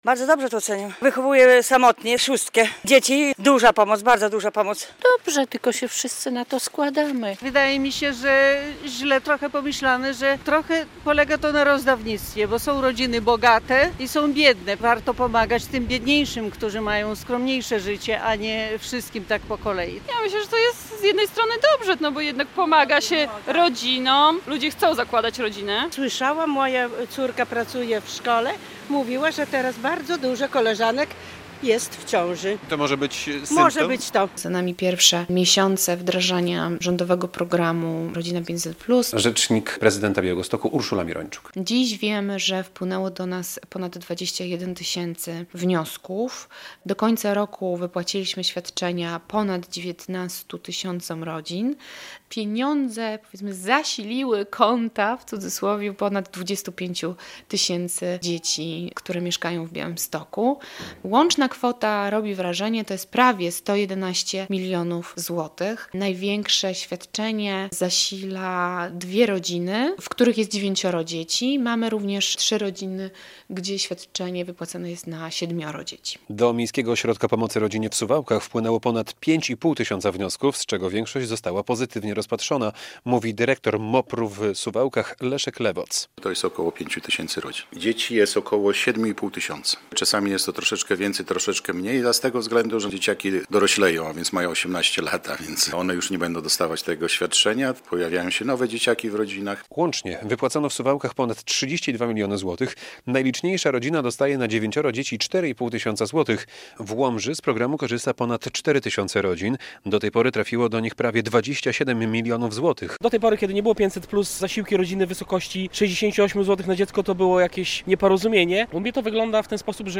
Podsumowanie działania programu "Rodzina 500+" - relacja